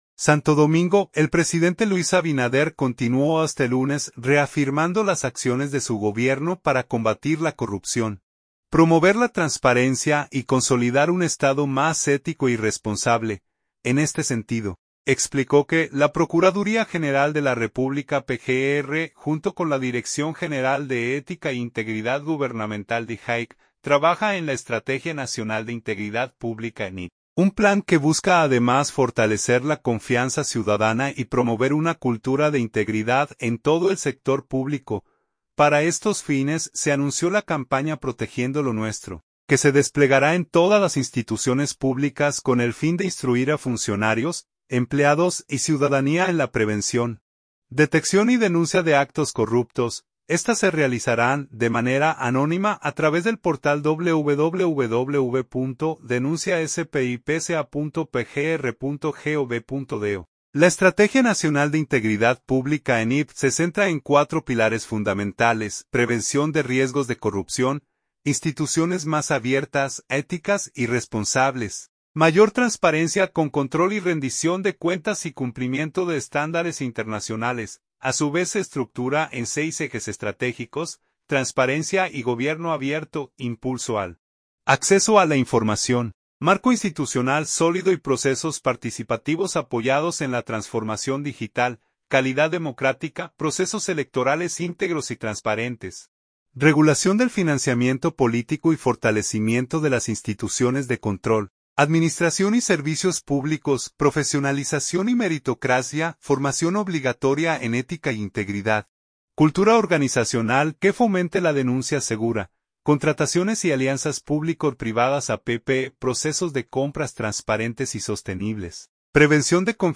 Durante sus palabras en LA Semanal con la Prensa, el jefe de Estado detalló los principales tipos penales vinculados a la corrupción administrativa y sus sanciones: